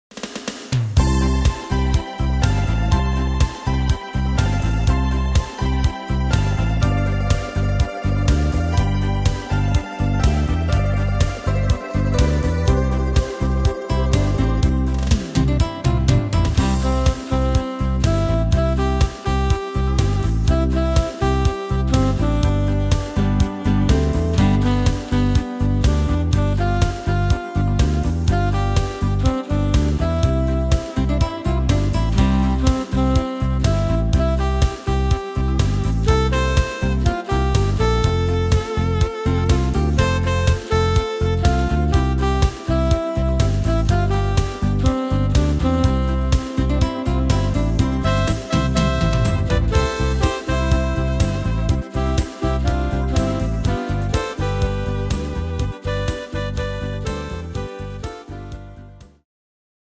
Tempo: 123 / Tonart: C-Dur